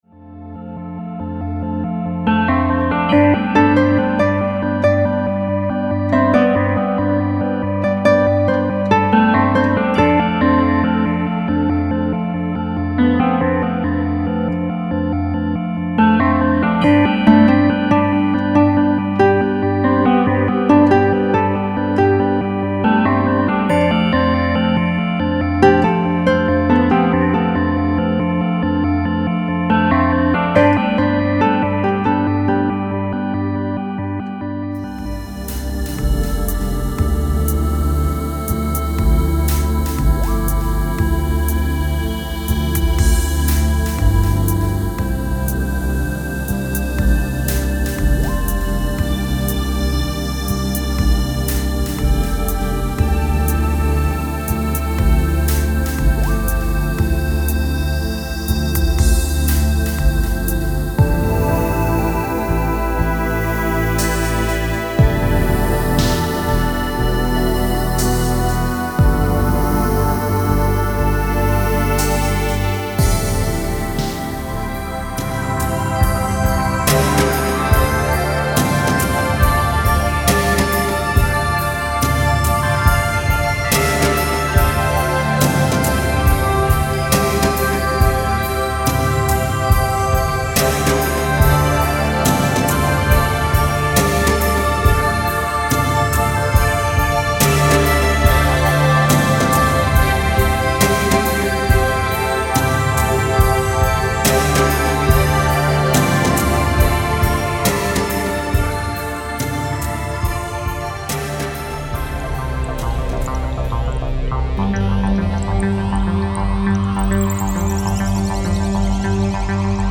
Moog „The Rogue“, Roland Juno 106, Roland Gaia SH-01,
Nord Electro 5D, Roland Fantom X6, Steinberg Cubase 10,